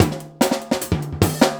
LOOP39--01-L.wav